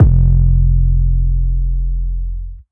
Fuck You 808.wav